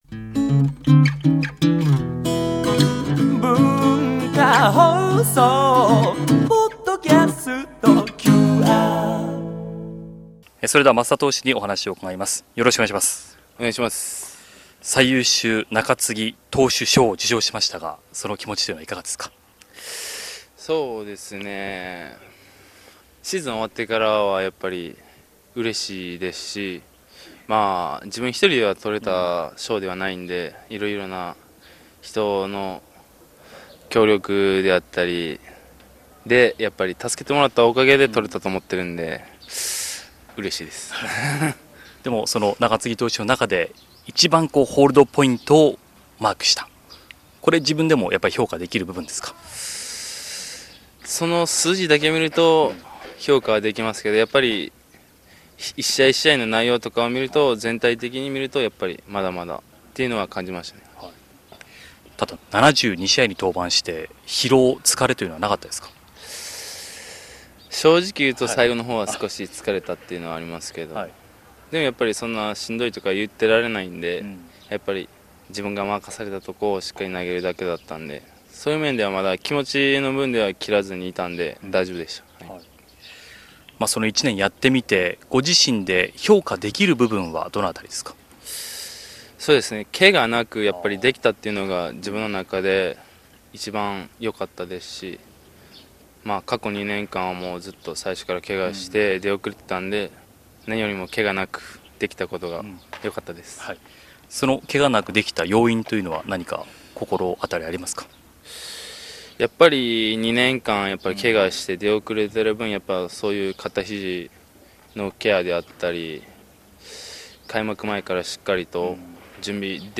◆タイトルホルダーインタビュー～増田達至～